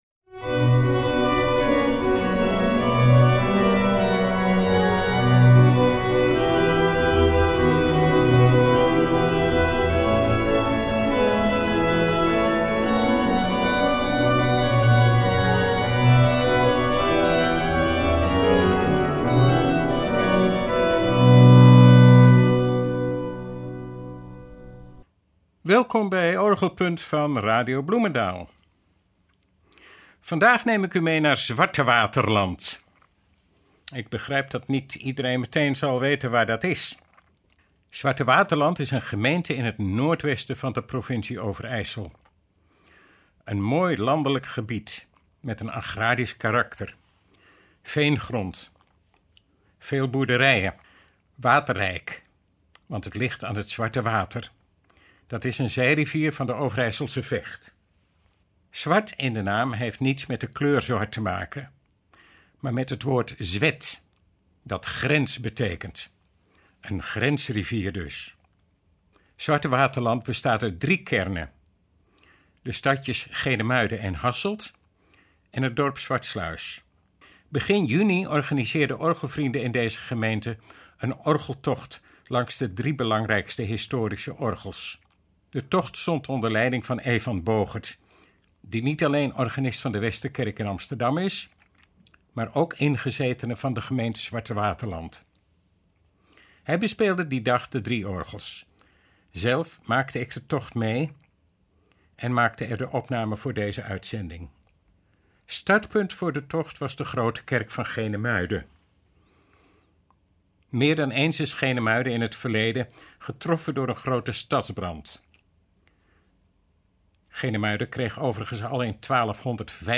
Opnamen van die dag zijn in deze uitzending te beluisteren. Luister naar improvisaties in verschillende stijlen over bekende liederen en composities van Jan Zwart, Johann Jakob Froberger, César Franck en een orgelbewerking van het openingsdeel van Beethovens “Eroïca-symfonie”.